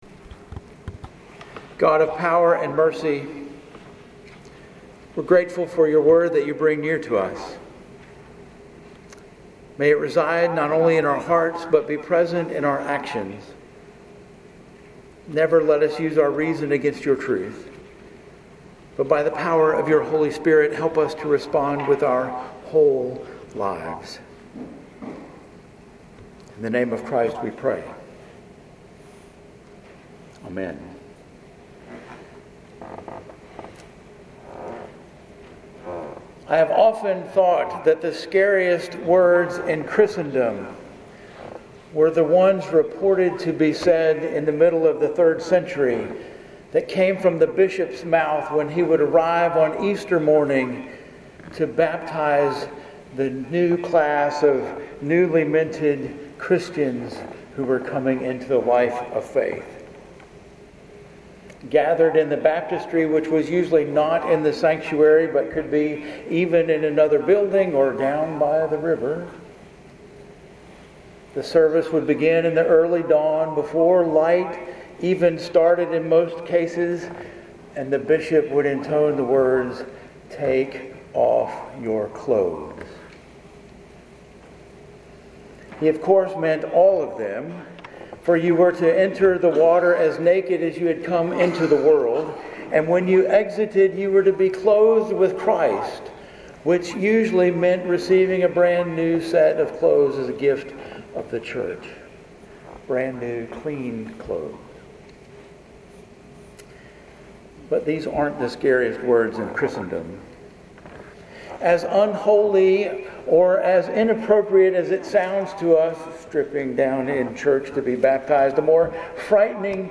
Sermons at First Presbyterian Church El Dorado, Arkansas